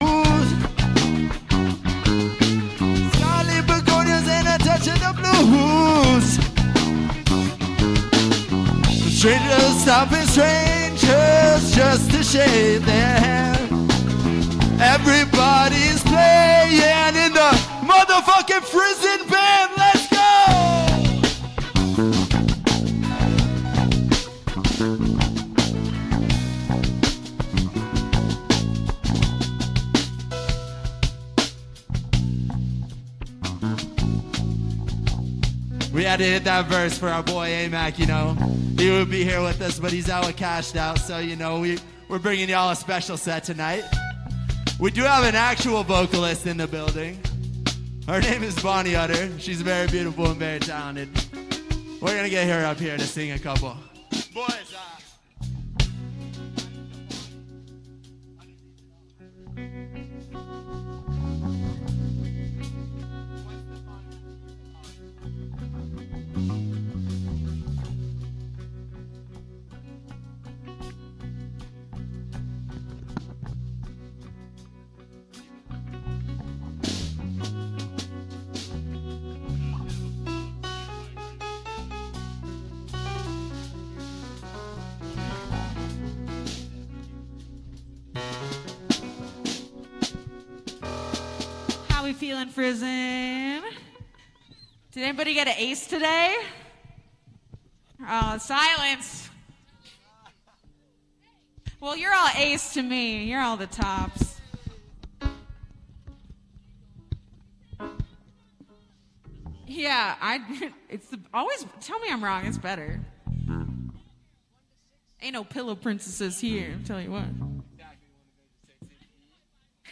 Live Karaoke every Wednesday through Saturday night 9-1